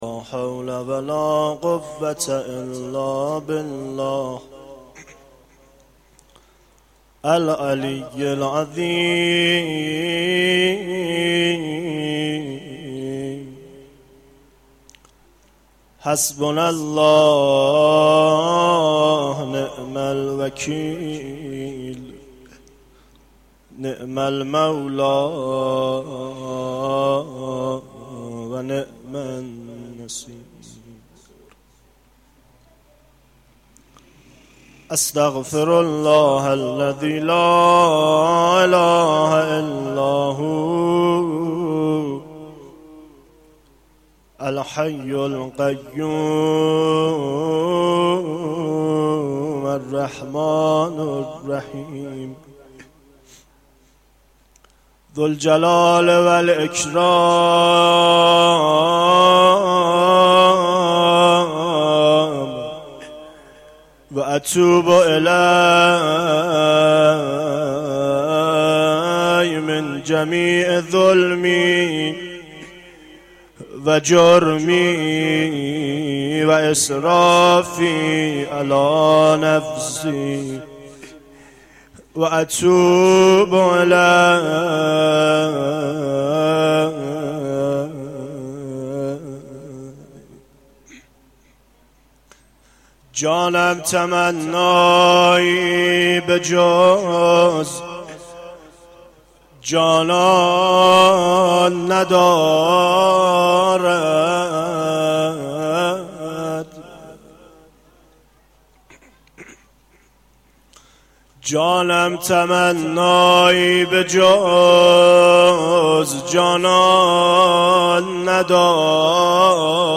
مناجات 4
مداحی